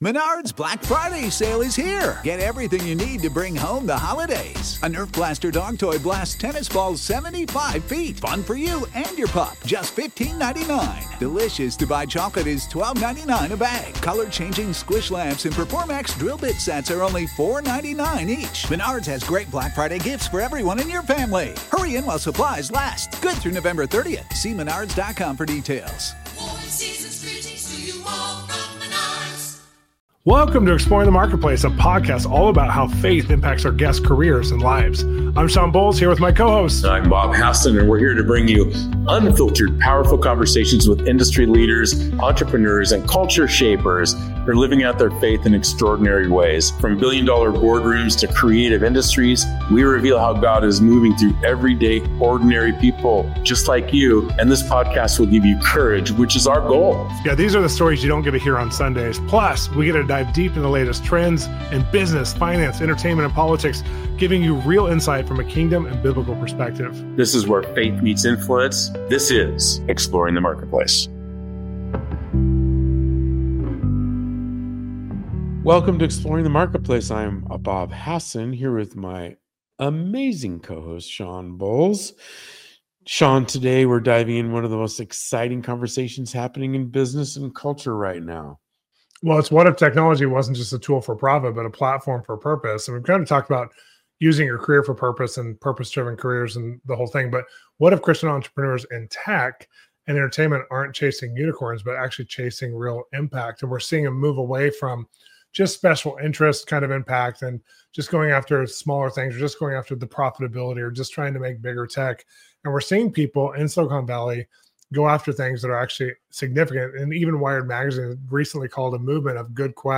You won’t want to miss this inspiring conversation!